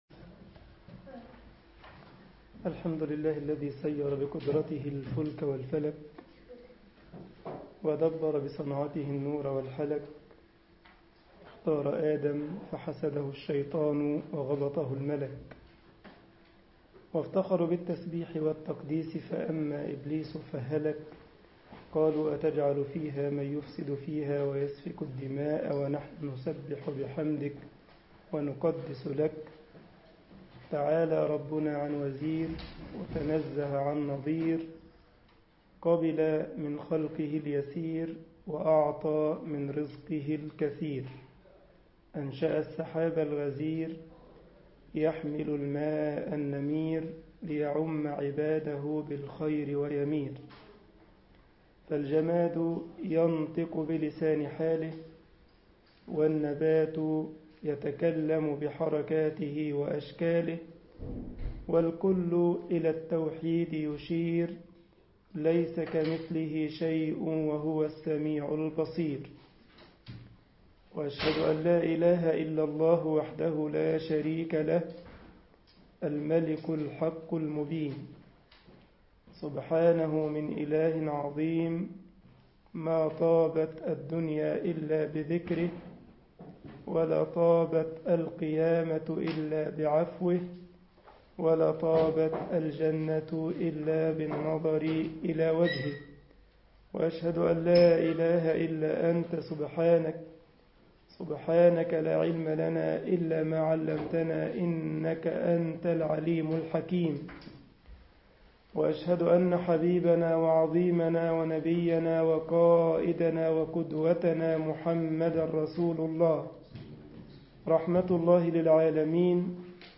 مسجد الجمعية الإسلامية بالسارلند ـ ألمانيا درس